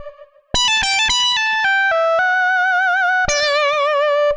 Index of /musicradar/80s-heat-samples/110bpm
AM_CopMono_110-E.wav